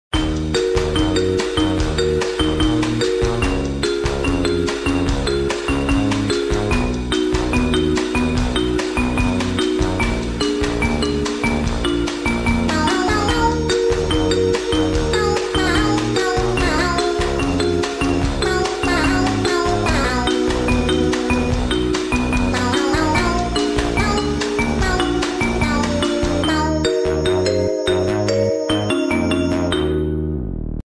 Doors